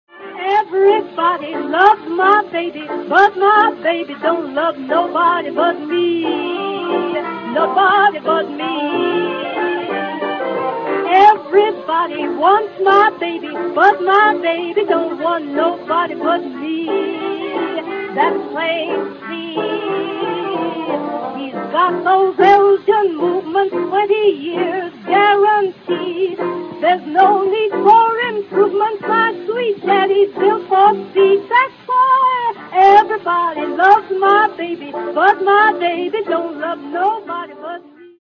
seguito dal chorus della solita scialba cantante di turno